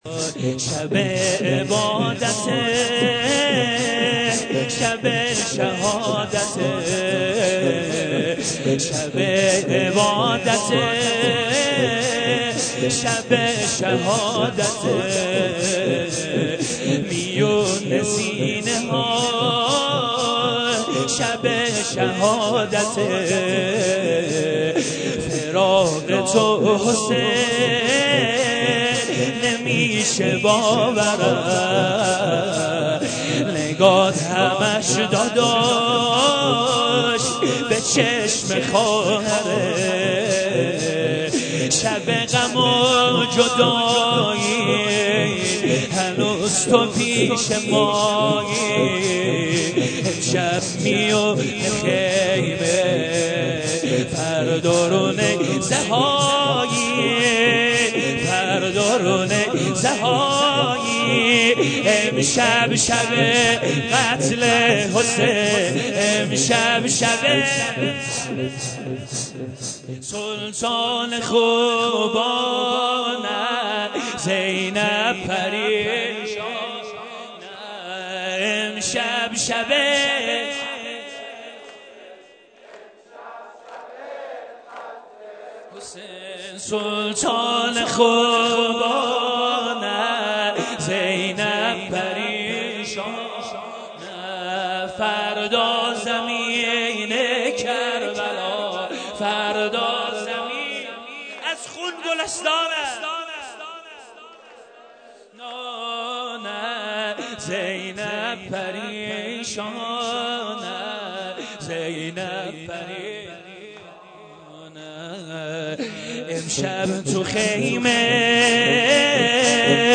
صوت شب عاشورا حسینی محرم ۱۴۰۰
مداحی
سینه زنی بخش اول